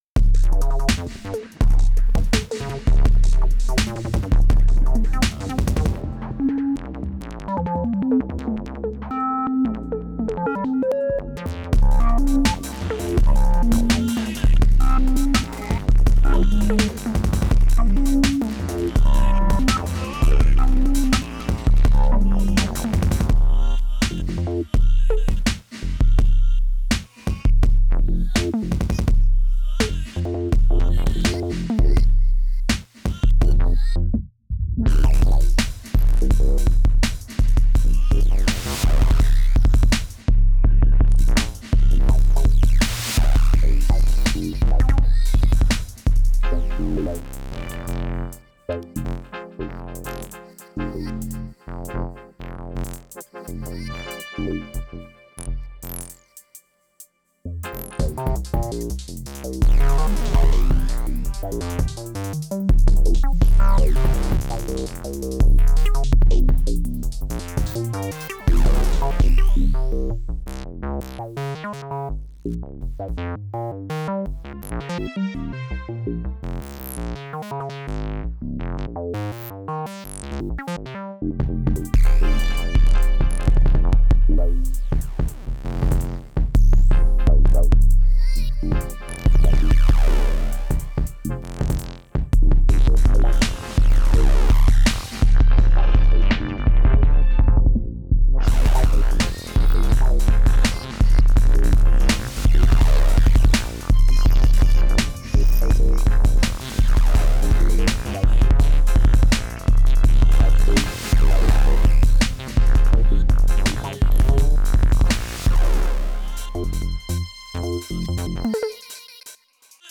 Digitakt soloing through the Syntakt filter.